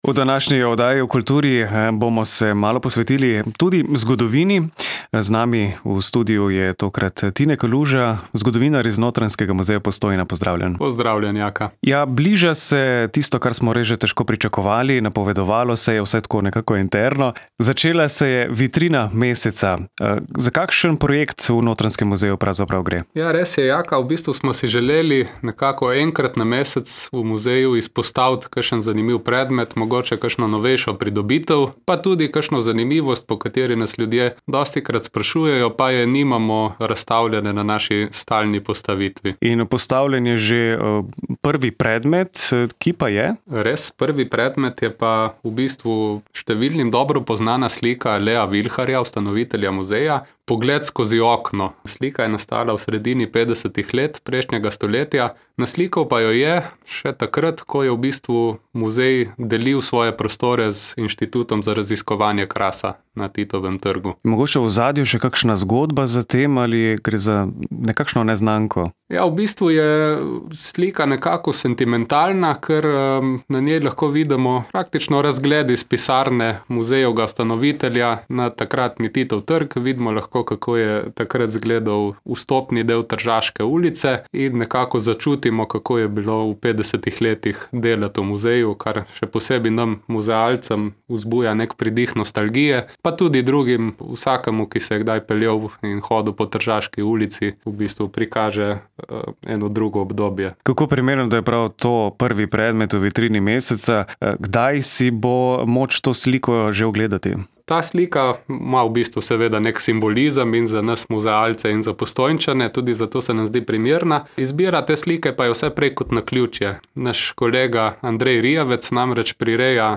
Več pa v kratek pogovoru.